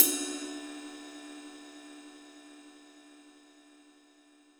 • Ride Sound Clip D Key 04.wav
Royality free ride cymbal single shot tuned to the D note. Loudest frequency: 9492Hz
ride-sound-clip-d-key-04-Rgy.wav